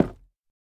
Minecraft Version Minecraft Version snapshot Latest Release | Latest Snapshot snapshot / assets / minecraft / sounds / block / chiseled_bookshelf / break2.ogg Compare With Compare With Latest Release | Latest Snapshot